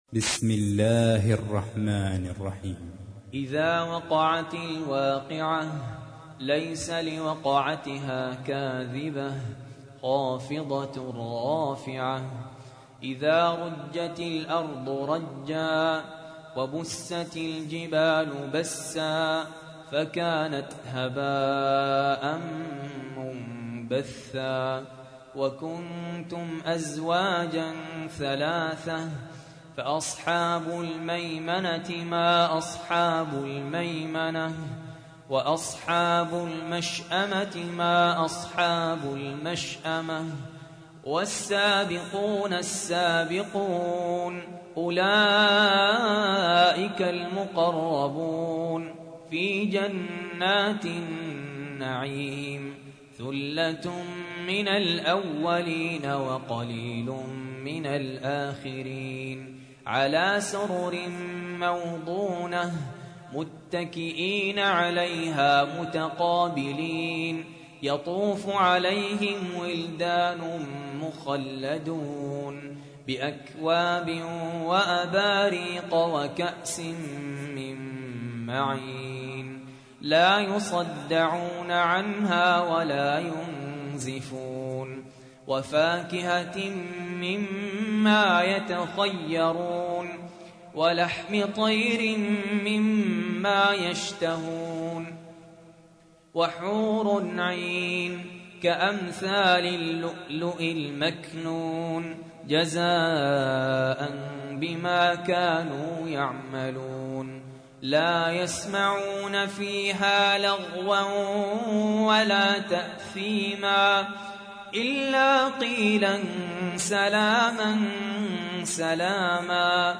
تحميل : 56. سورة الواقعة / القارئ سهل ياسين / القرآن الكريم / موقع يا حسين